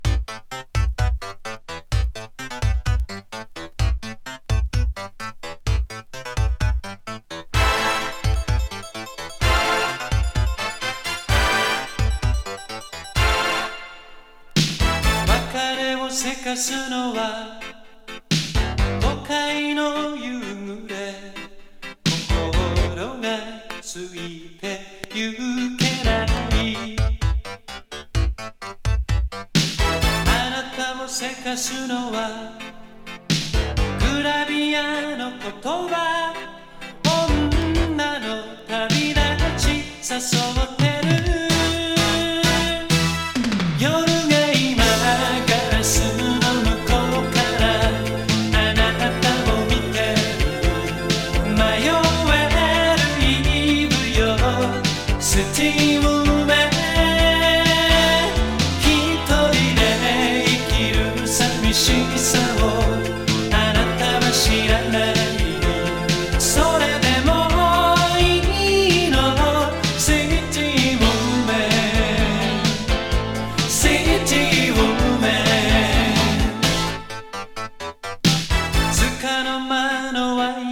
繊細さ感じるヴォーカルが魅力のエレクトロ歌謡。